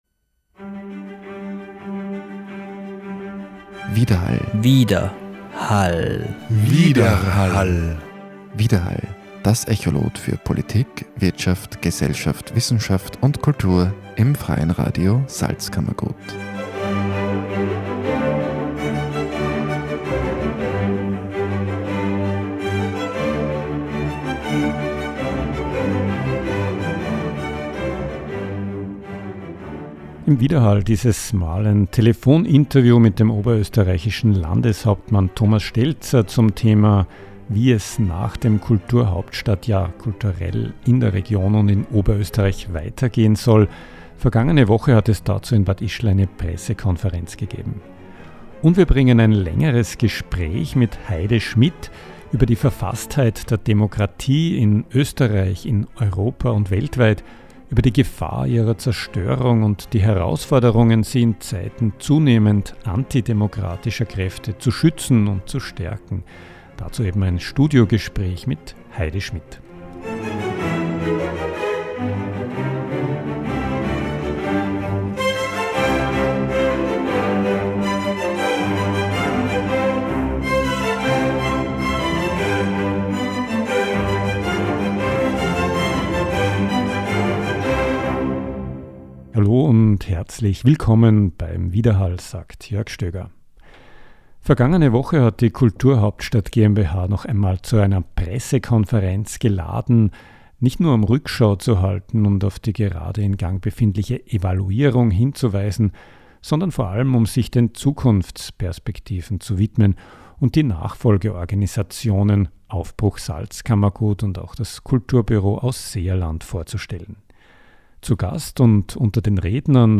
Im Telefoninterview geht er auf die Rolle der Landespolitik bei der Kulturentwicklung im Zusammenhang mit den Impulsen des Kulturhauptstadtjahres ein.